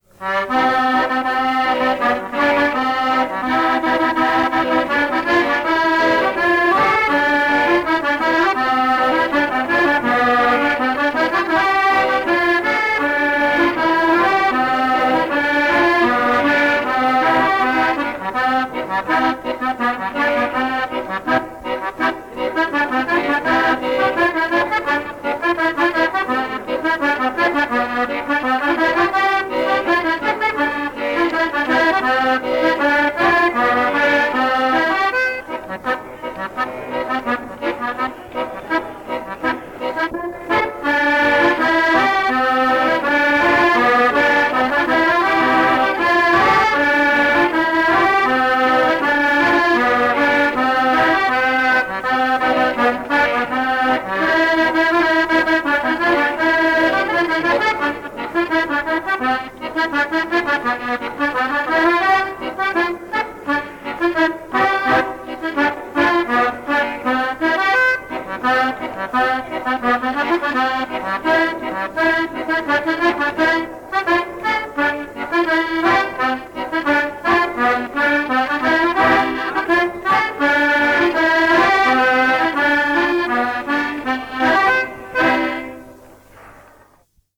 Especie: chotis inglés
acordeón de dos hileras
Formato original de la grabación: cinta magnética Audiotape a 9,5 cm/s